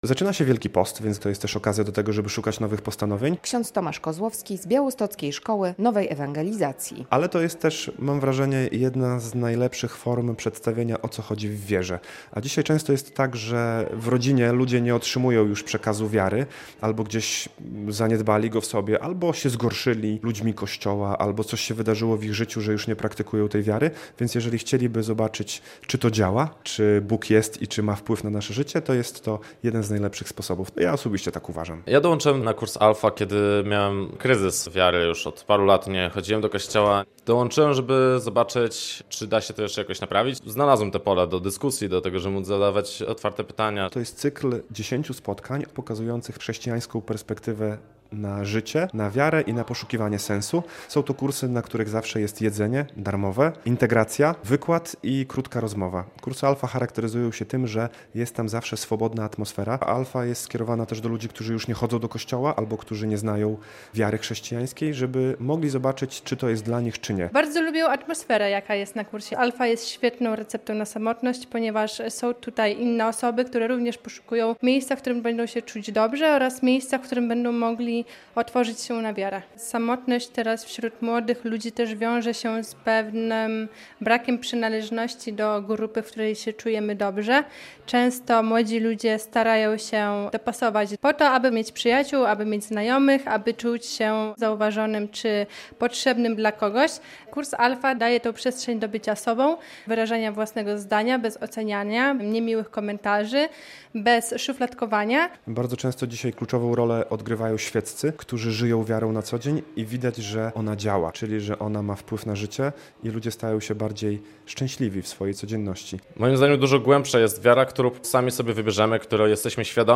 Wielkopostne Kursy Alpha - relacja